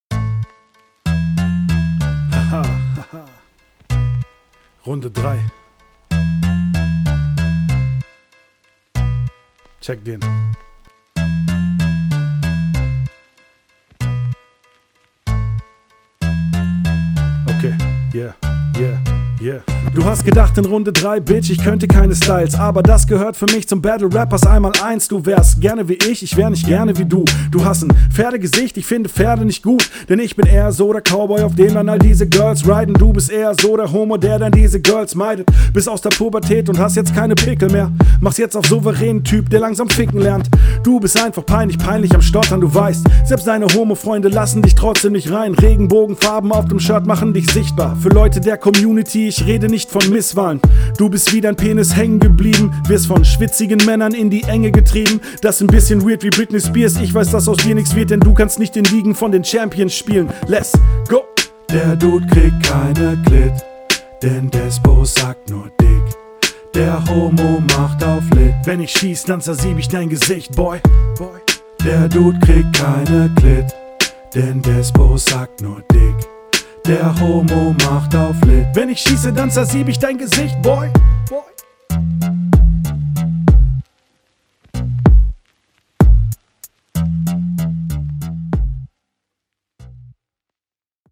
Chillige runde nice geflowt, solide durch gerappt freshes teil!
Text fett, Stimme auch fett, Flow nice.
Netter Stylewechsel.